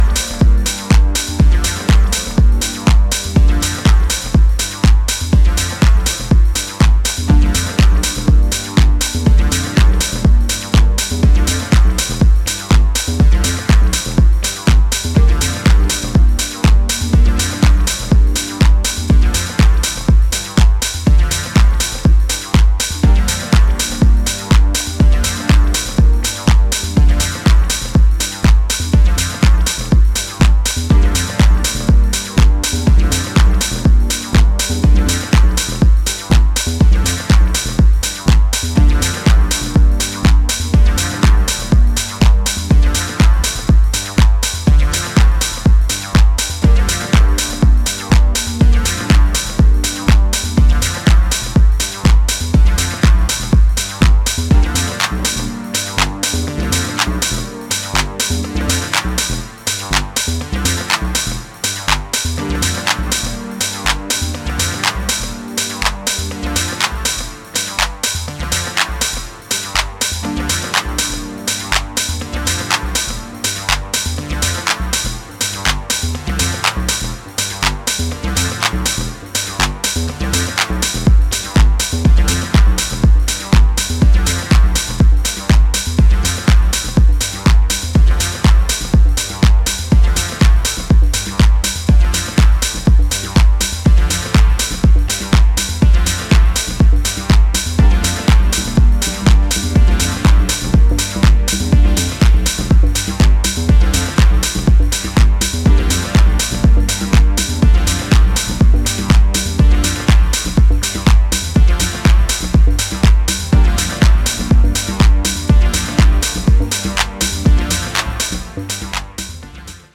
極めてヘヴィなボトムとスペーシーなシンセが対比する
ウォームなディープ・ハウス集です！